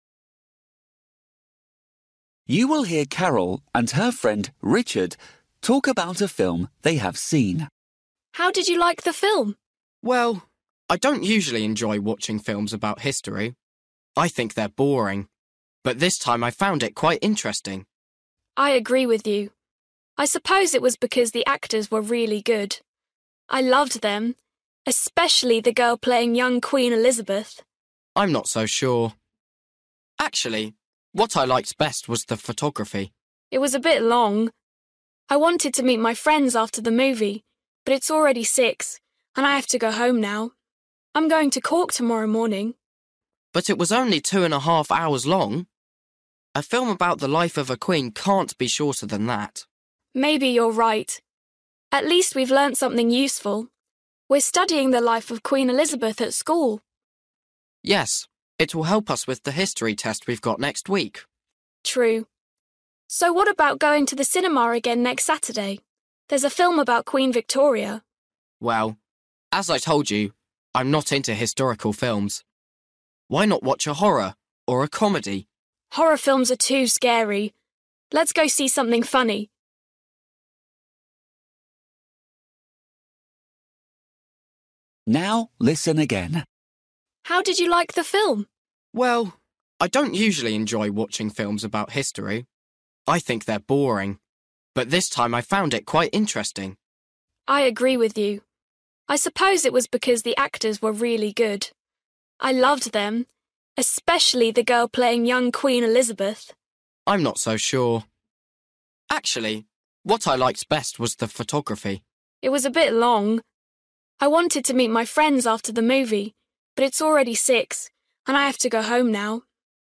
Bài tập trắc nghiệm luyện nghe tiếng Anh trình độ sơ trung cấp – Nghe một cuộc trò chuyện dài phần 34